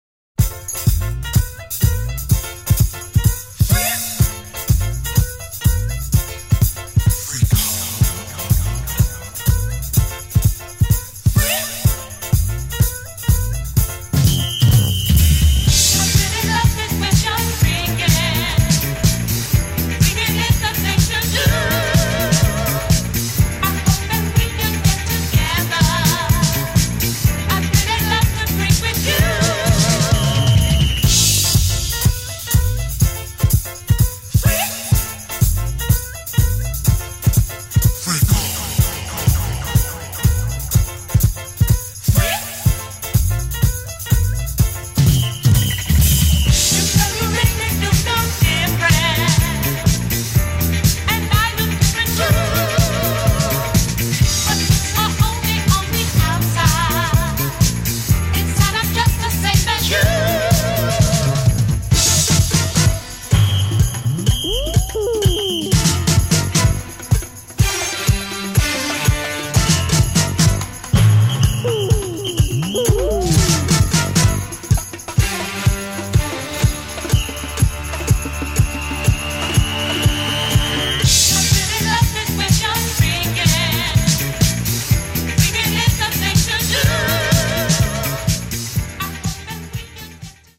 4 monstruous tracks straight from the basement